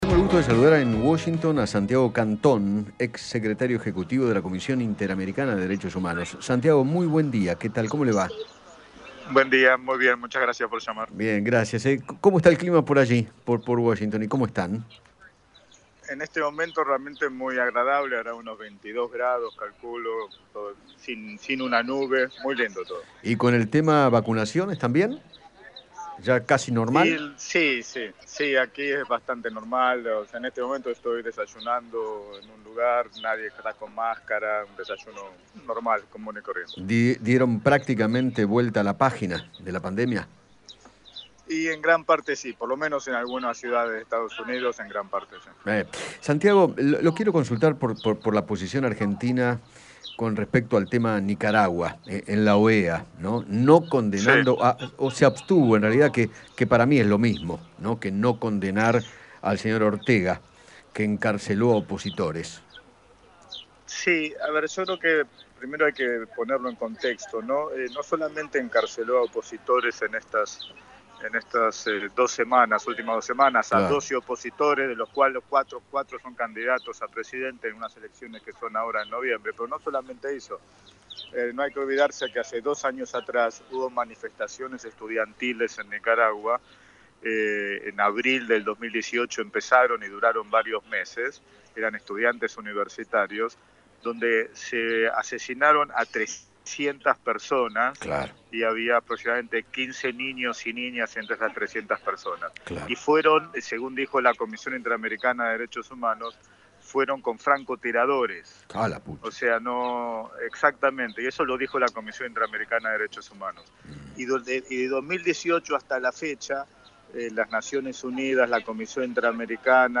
Santiago Cantón, ex secretario ejecutivo de la Comisión Interamericana de Derechos Humanos, conversó con Eduardo Feinmann acerca de la abstención de la Argentina ante la violación de derechos humanos en Nicaragua y expresó que “lo lamentable del comunicado es que hace uso de unos términos que siempre queremos abandonar”.